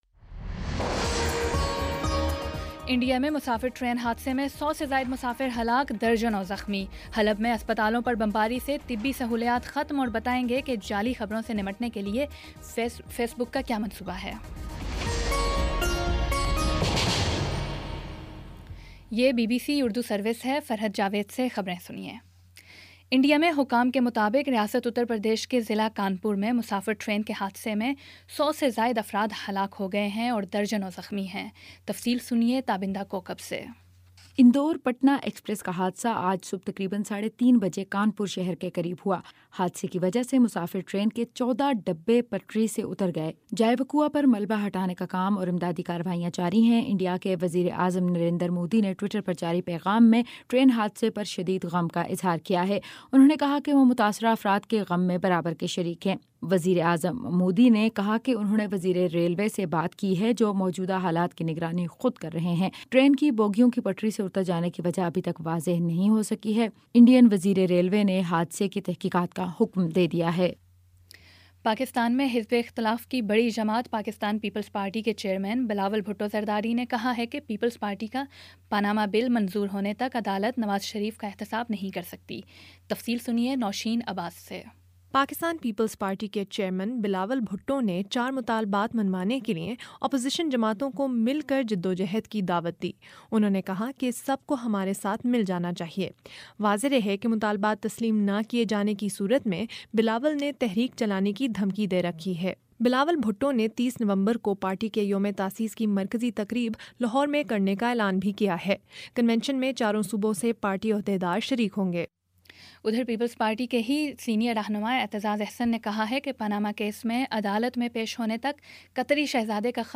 نومبر 20 : شام پانچ بجے کا نیوز بُلیٹن